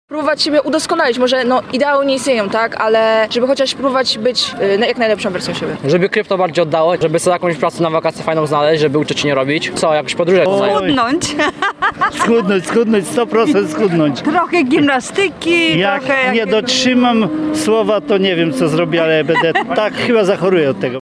Noworoczne postanowienia. Cele, które sobie stawiamy, muszą być realistyczne [SONDA]